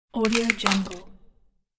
دانلود افکت صوتی ترک نرم ۵
آهنگ صوتی رایگان Soft Crack 1 یک گزینه عالی برای هر پروژه ای است که به صداهای بازی و جنبه های دیگر مانند sfx، صدا و کرک نیاز دارد.